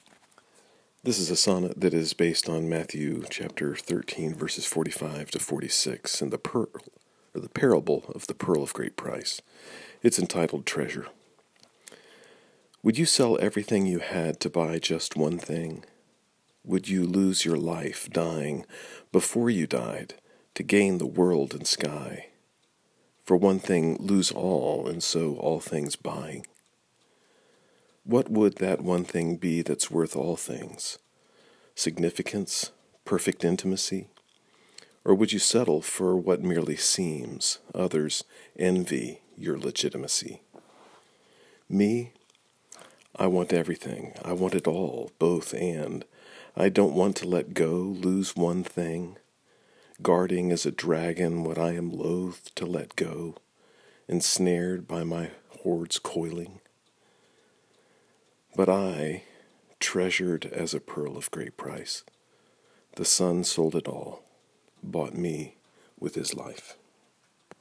If it’s helpful, you may listen to me read the sonnet via the player below.